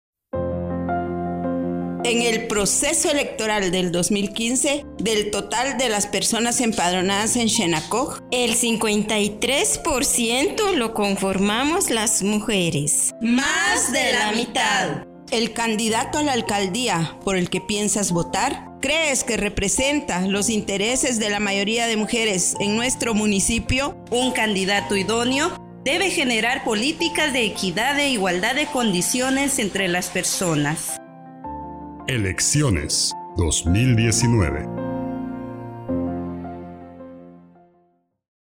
Spots Radial